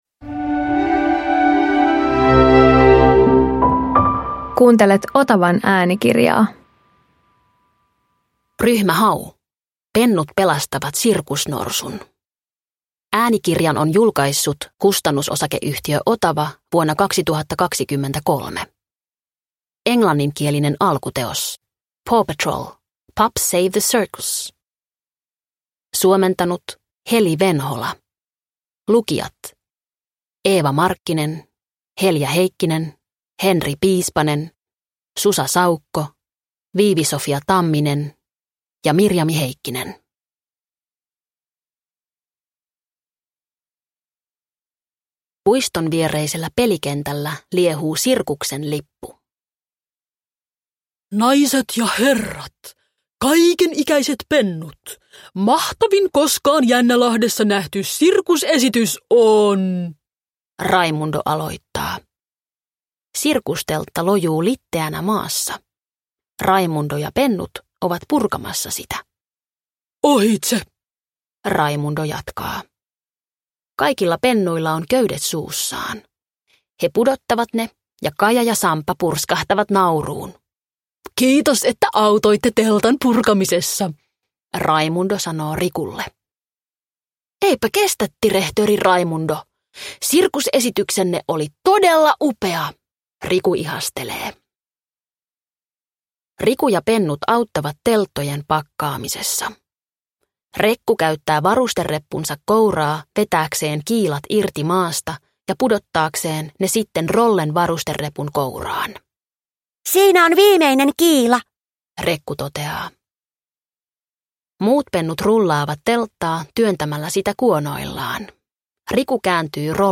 Ryhmä Hau Pennut pelastavat sirkusnorsun – Ljudbok – Laddas ner